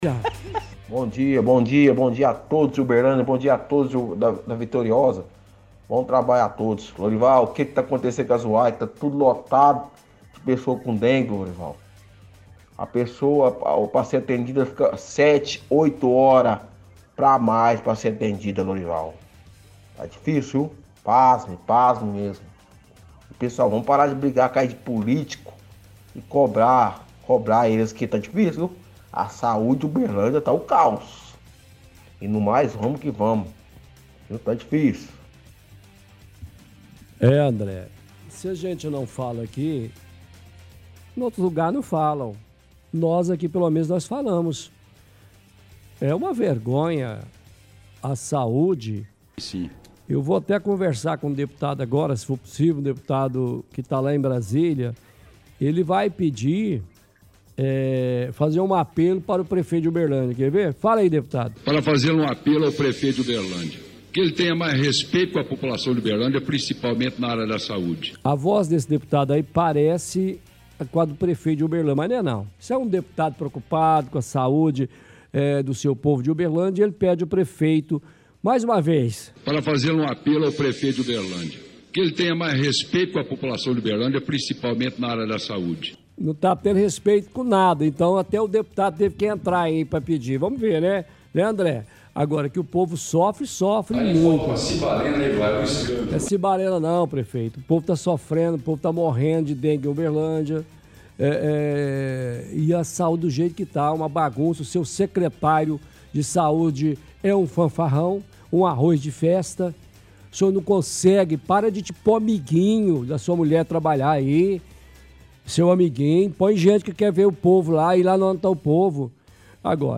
– Ouvinte reclama da saúde na cidade.
– Transmissão de áudio do prefeito, enquanto deputado, pedindo que o prefeito de Uberlândia cuide mais da saúde.